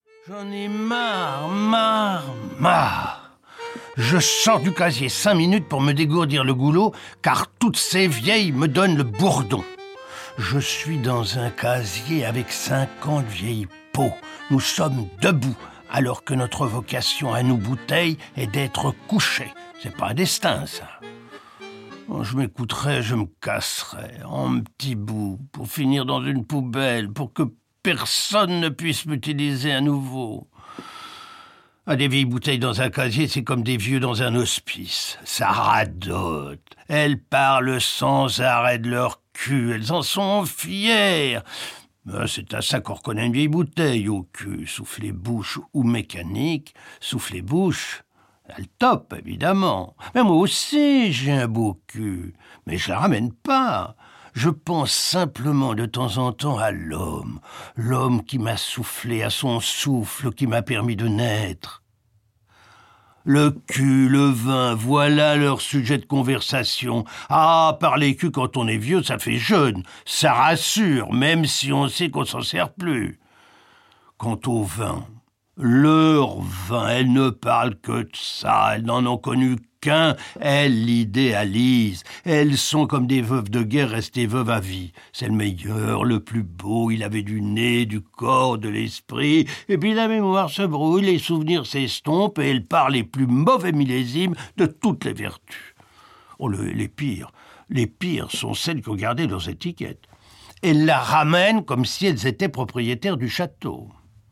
Diffusion distribution ebook et livre audio - Catalogue livres numériques
Lire un extrait Jean-Pierre Coffe Descente aux plaisirs - Souvenirs d'une bouteille Le Livre Qui Parle Date de publication : 2012-09-13 Ce monologue, ponctué de musiques, met en scène une bouteille qui raconte sa vie et son grand amour pour son vin, un rouge, et sa secrète attirance pour les blancs… L'action se déroule dans une cave bien entretenue, la cave d'un homme de qualité. Jean-Pierre Coffe est à la fois l’auteur et l’interprète de La Bouteille, ce personnage qui, de moments de tendresse en moments de colère ou de joie, va nous raconter sa vie : naissance, rencontre, amour, rupture, jalousie, bonheur, amitié, vieillesse... une bouteille profondément humaine, fragile, touchante et attachante.